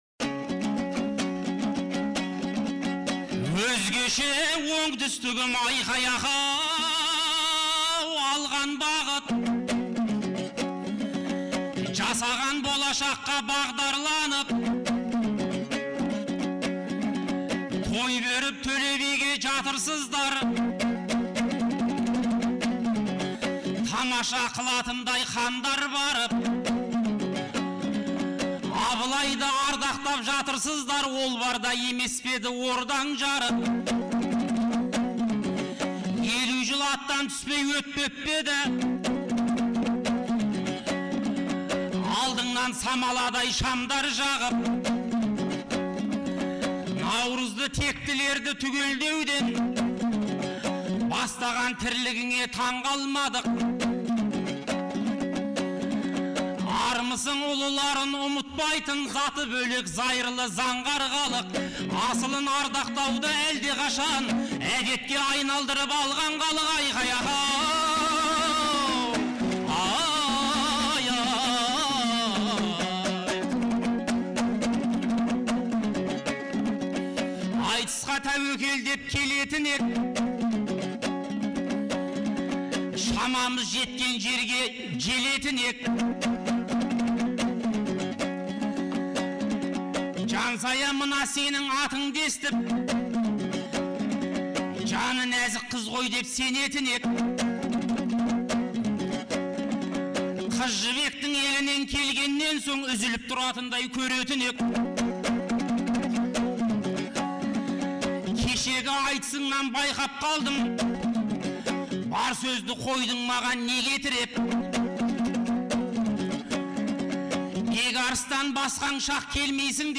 Наурыздың 15-16 күндері Шымкент қаласында екі күнге созылған республикалық «Наурыз» айтысы өткен болатын. 2004 жылдан бері тұрақты өтіп келе жатқан айтыс биылғы жылы Төле бидің 350 және Абылай ханның 300 жылдықтарына арналды.